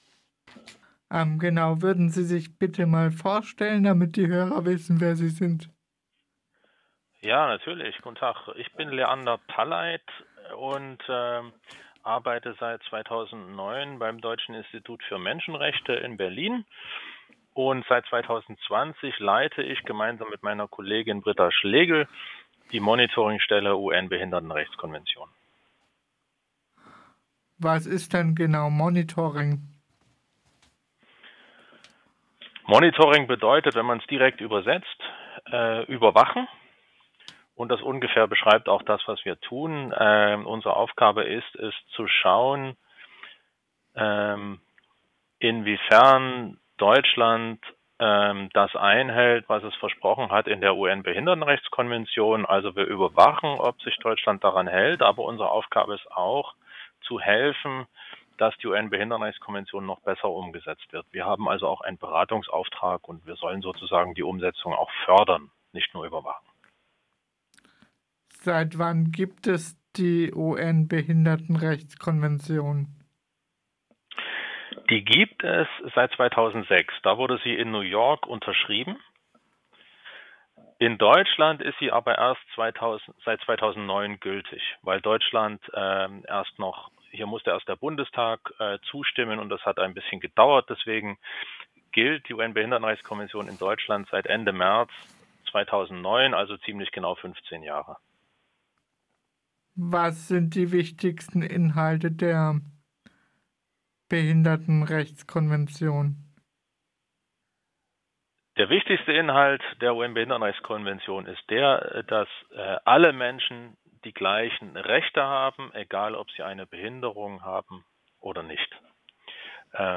Interview zur UN Behinderten-Rechtskonvention